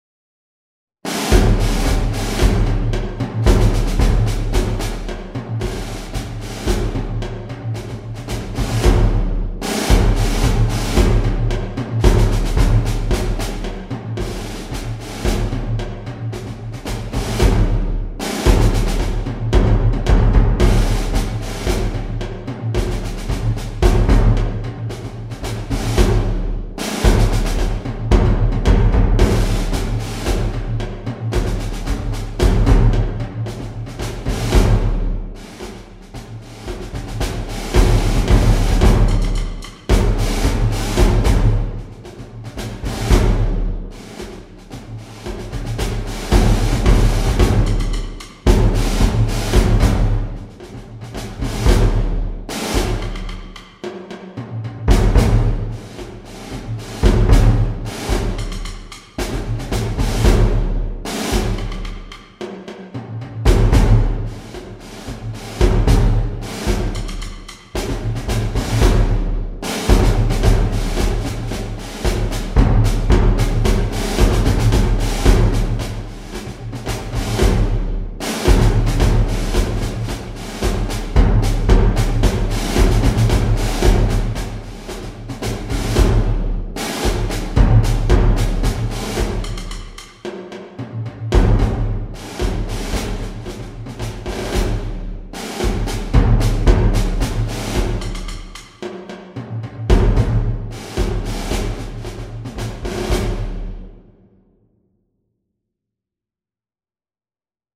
Marche de défilé pour batterie Anglaise
– Parties séparées : Tambours, Toms, Gr. caisse